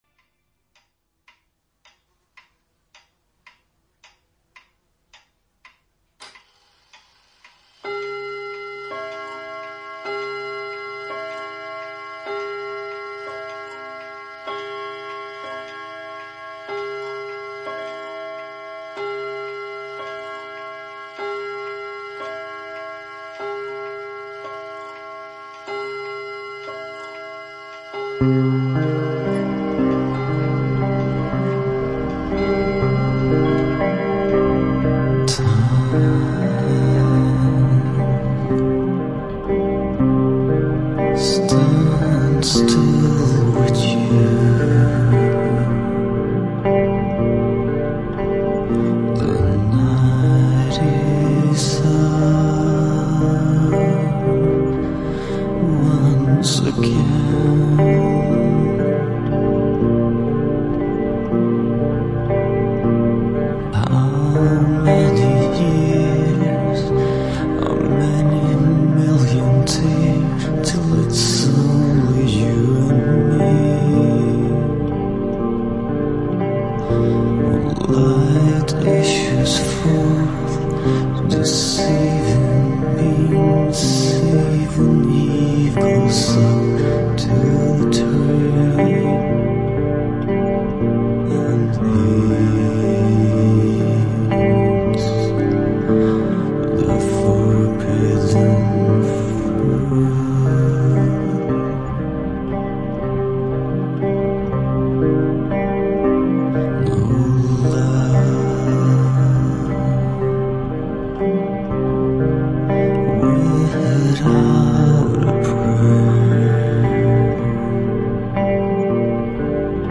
Зухвалий настрій в цієї пісні! 16 give_rose